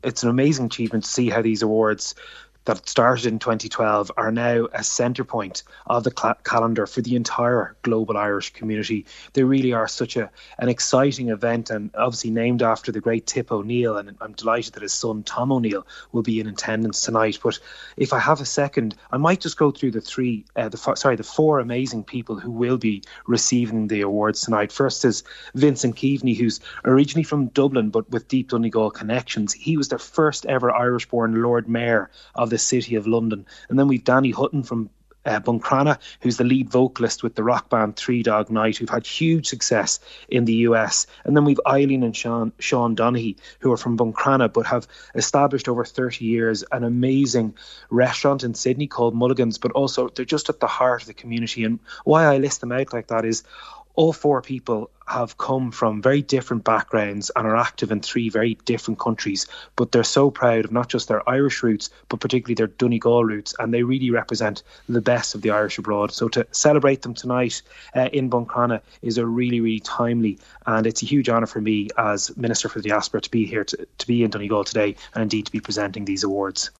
Diaspora Minister Neale Richmond has paid tribute to those who will be recognised at tonight’s ceremony: